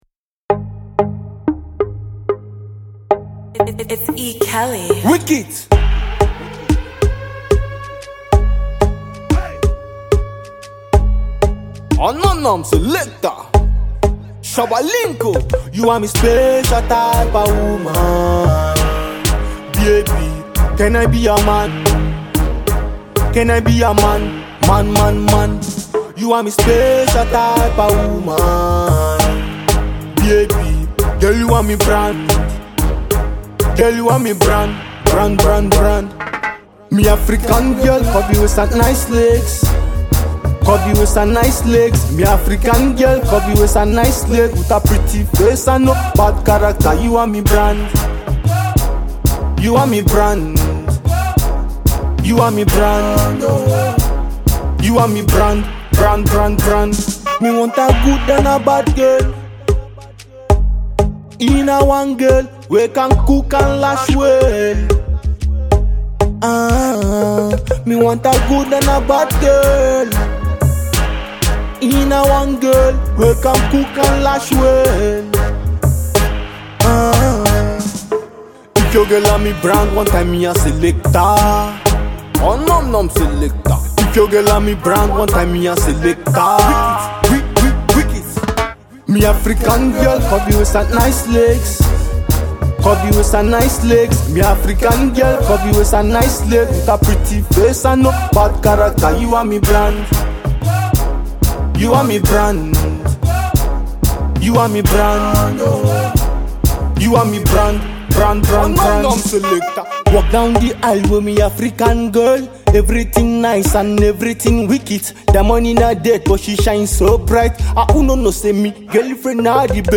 Afro dancehall tune